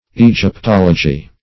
Search Result for " egyptology" : Wordnet 3.0 NOUN (1) 1. archeology of ancient Egyptian artifacts ; The Collaborative International Dictionary of English v.0.48: Egyptology \E`gyp*tol"o*gy\, n. [Egypt + -logy.]